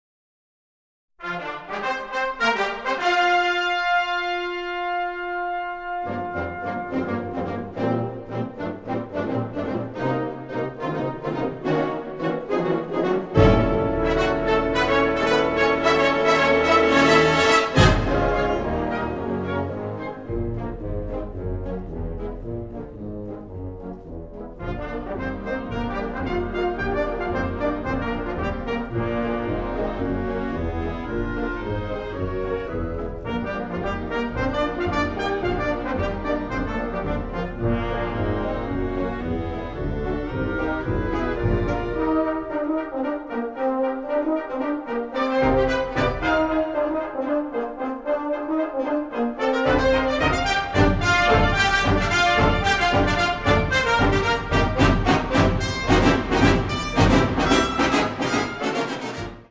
Kategorie Blasorchester/HaFaBra